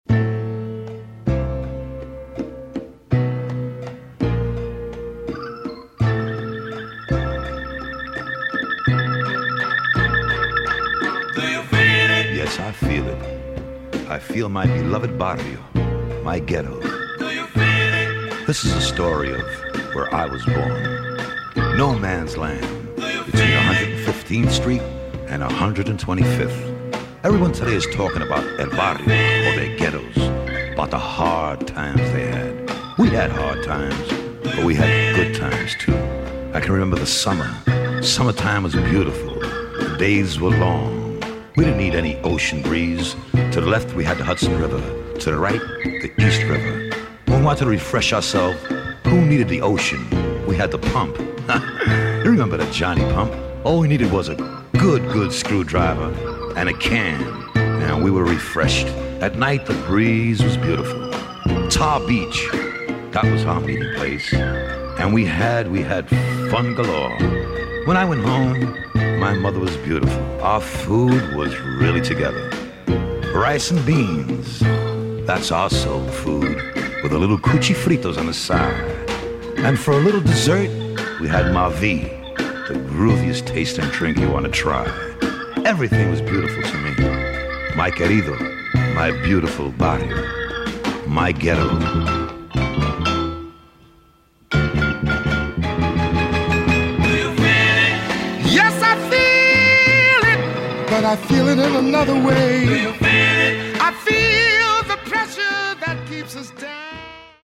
Stunning latin jazz from El Barrio.